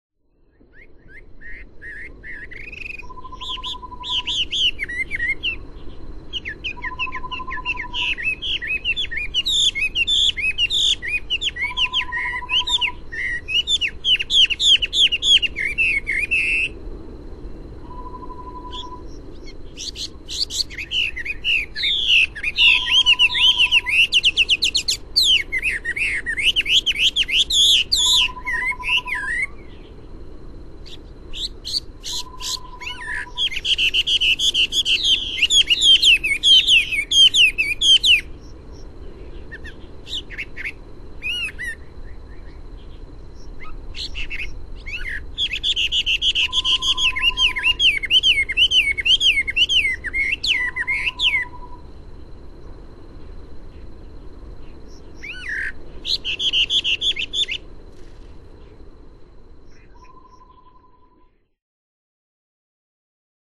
從清晨到黃昏的六段充滿鳥聲的自然錄音，搭配上27種鳥聲，最後以夜晚的貓頭鷹聲音做結尾，是最值得珍藏的自然聲音CD。